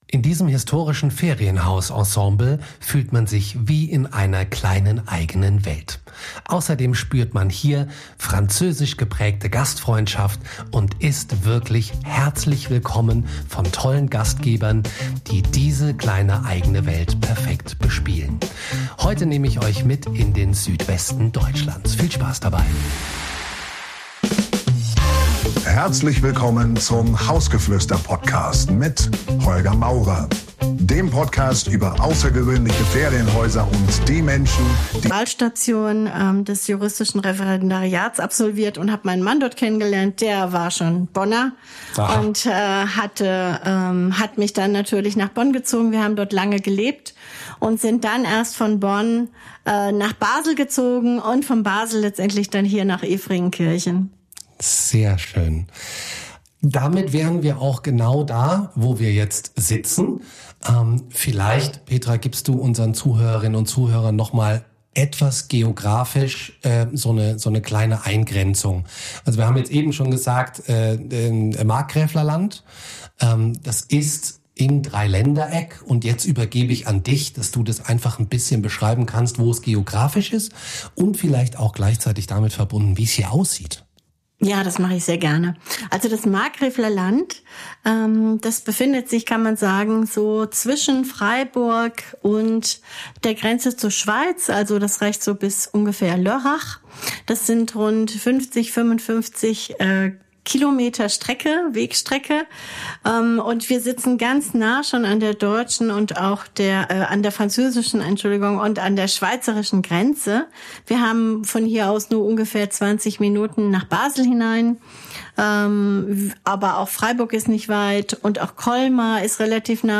Ein Gespräch über Architektur, Gastfreundschaft – und einen Ort, den man so schnell nicht vergisst.